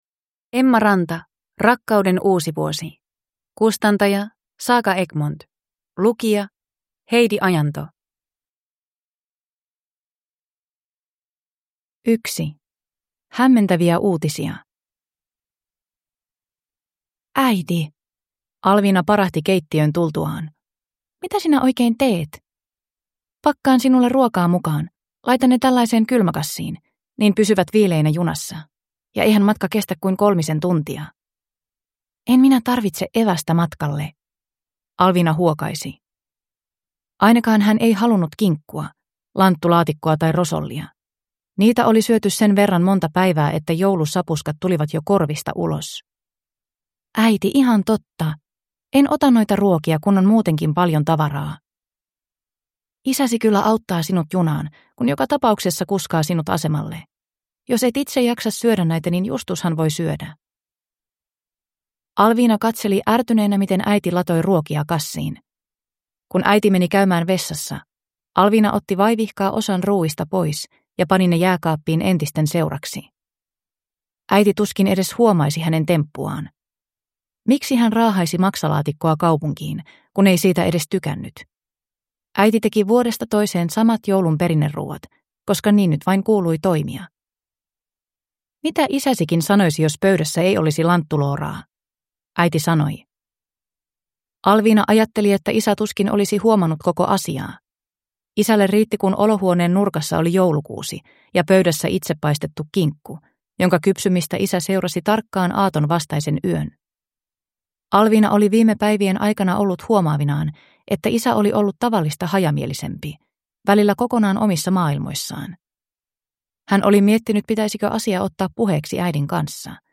Rakkauden uusi vuosi (ljudbok) av Emma Ranta | Bokon